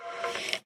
1.21.5 / assets / minecraft / sounds / mob / stray / idle4.ogg